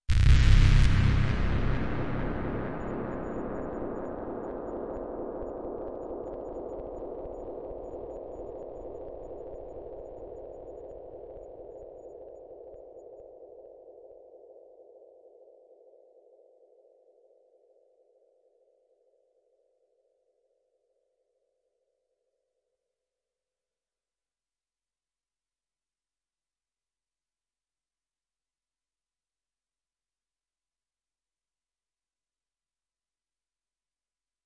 描述：合成工业声音设计
Tag: 合成器 块状 打击乐器 黑暗 金属 FX 空间 低音 命中 工业 PERC